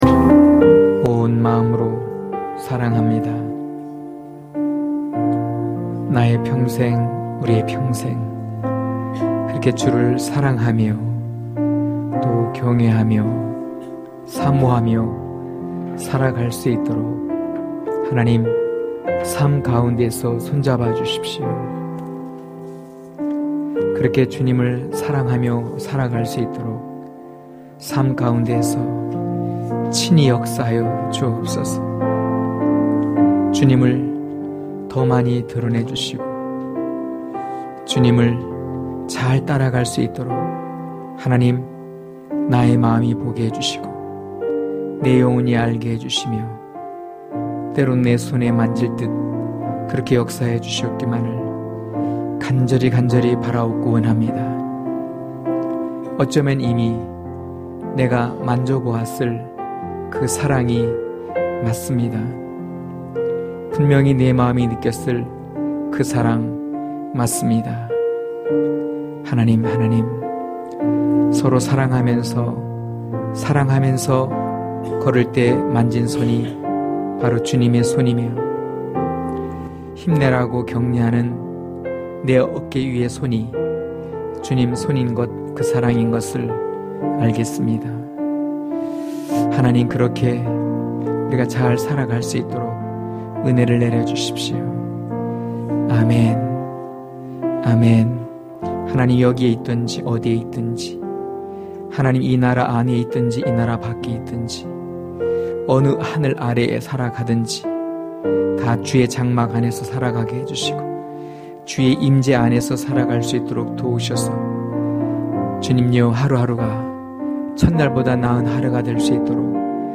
강해설교 - 13.사도 요한 속의 예수(요삼1장1-4절)